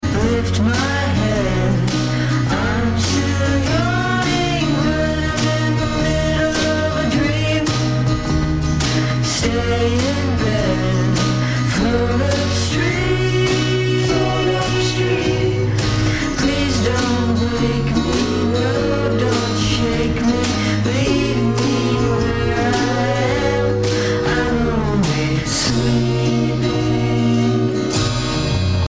pitched stuff up and down etc.